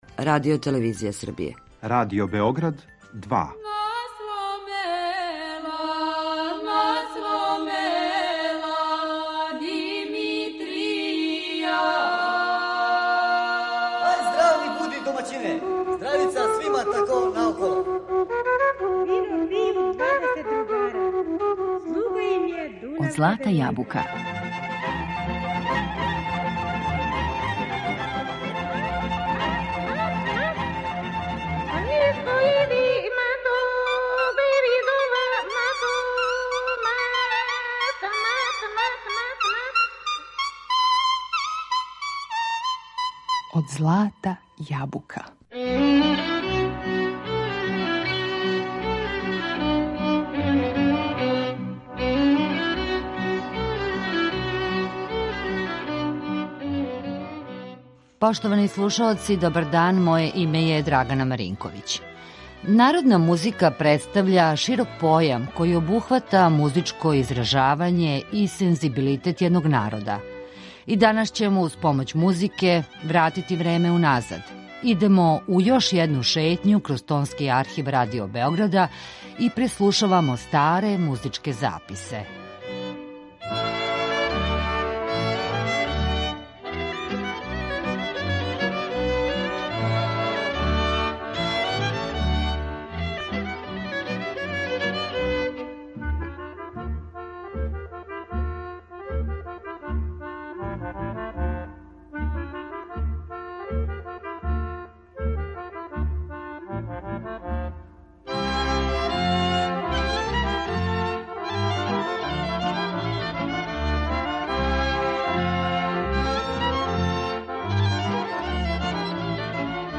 У данашњој емисији Од злата јабука, уз помоћ записа који се налазе у Звучном архиву Радио Београда, настављамо нашу музичку шетњу кроз време.